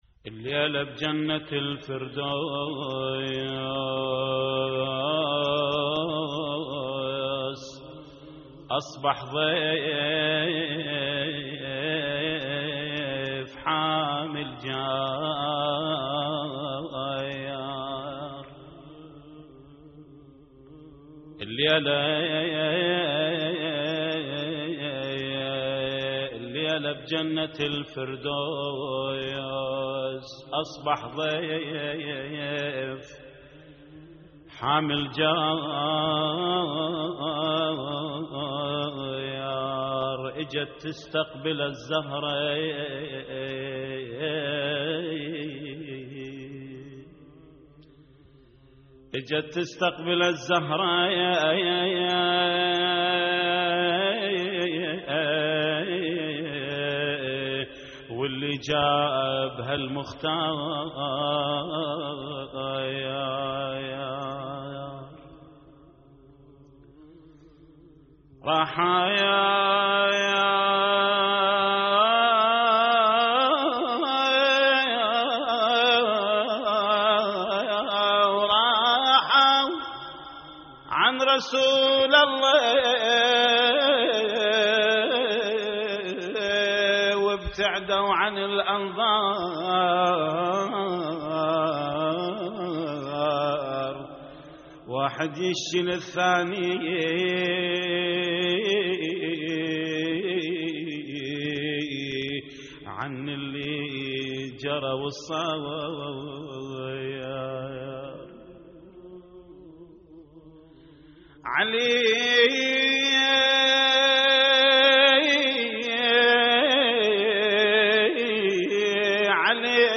استديو - نعي